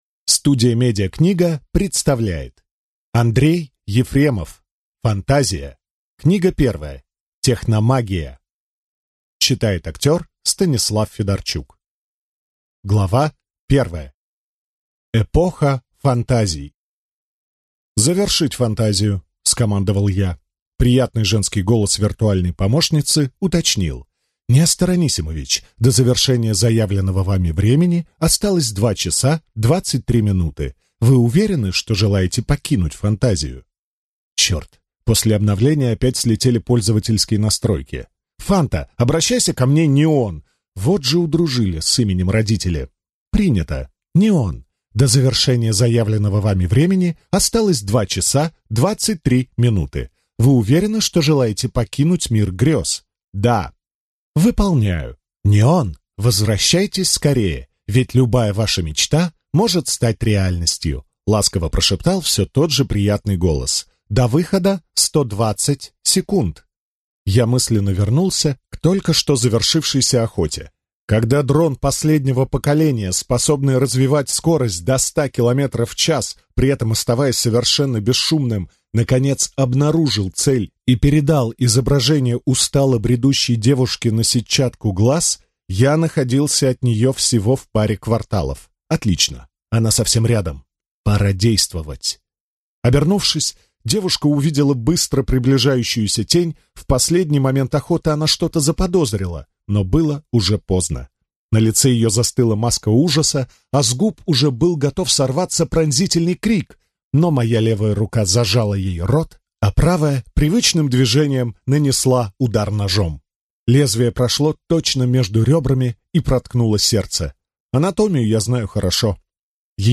Аудиокнига Фантазия. Книга 1. Техномагия | Библиотека аудиокниг